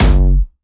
Jumpstyle Kick 1
2 F#1.wav